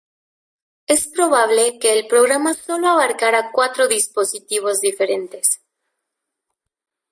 Masculino
/pɾoˈbable/